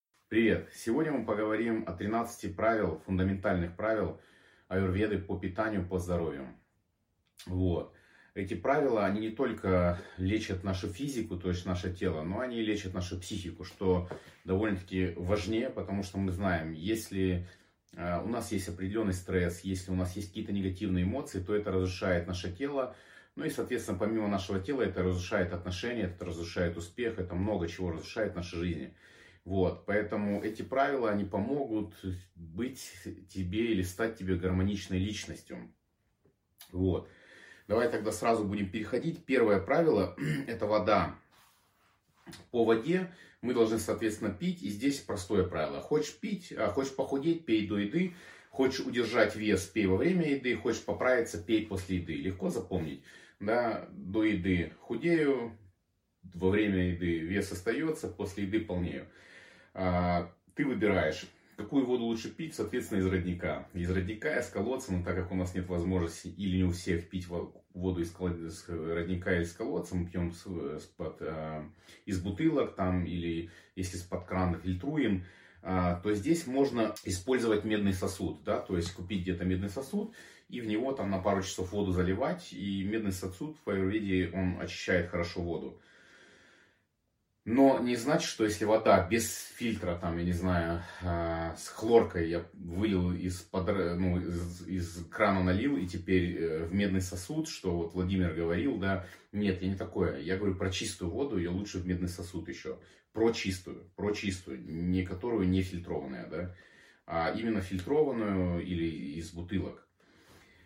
Аудиокнига 13 древних аюрведических правил питания | Библиотека аудиокниг